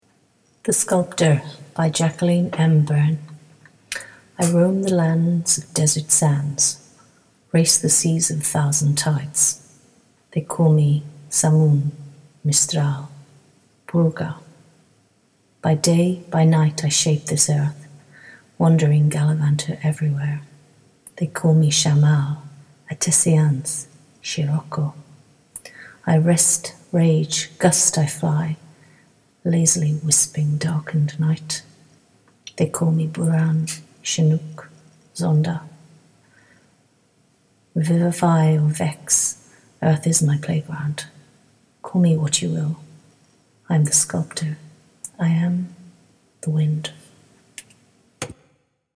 The Sculptor should be viewed while listening to the spoken recording below the image.